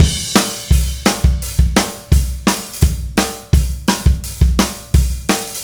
Indie Pop Beat 04 Crash.wav